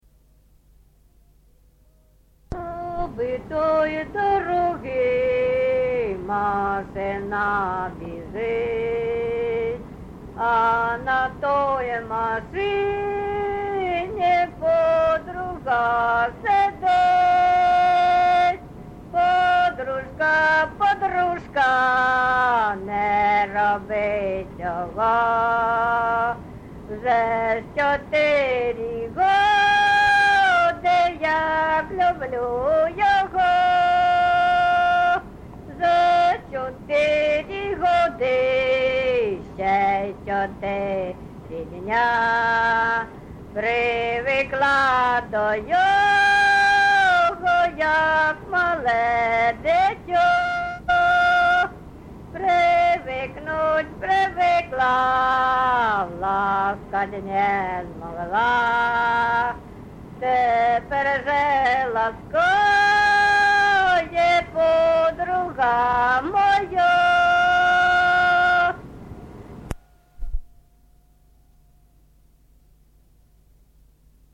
ЖанрПісні з особистого та родинного життя
Місце записус. Харківці, Миргородський (Лохвицький) район, Полтавська обл., Україна, Полтавщина